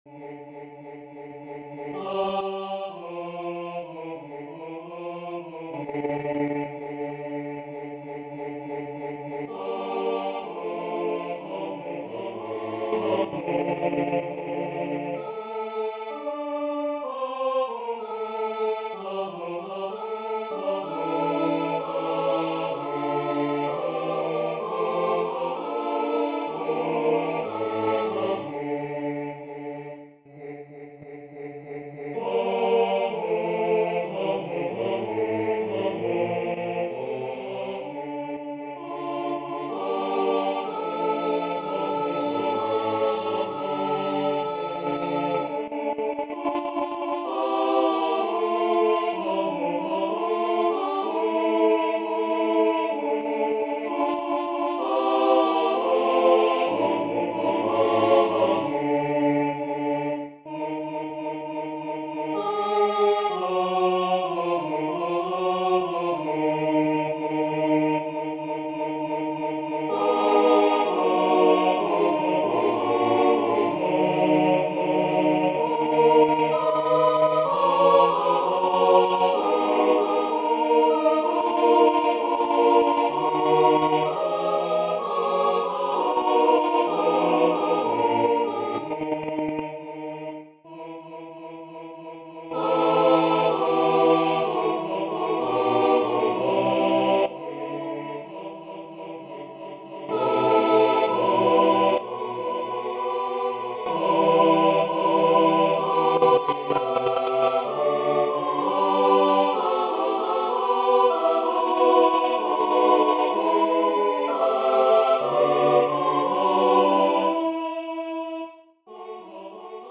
Аранжировка для хора